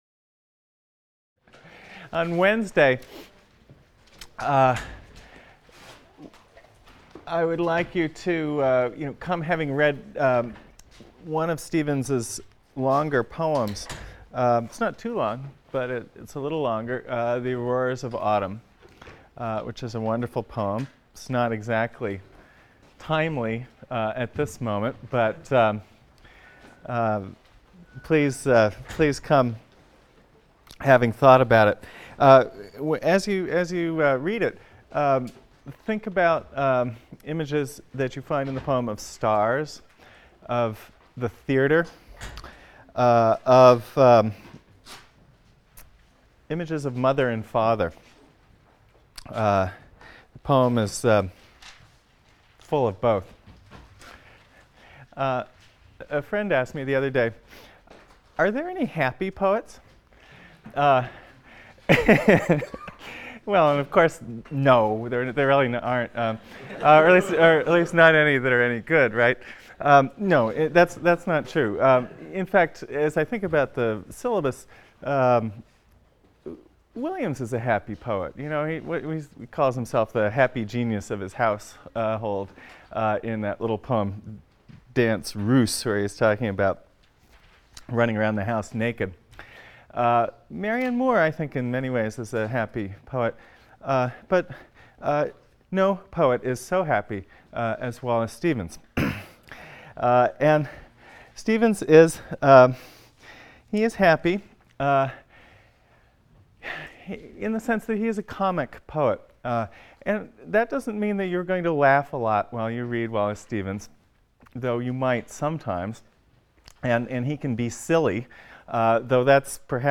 ENGL 310 - Lecture 19 - Wallace Stevens | Open Yale Courses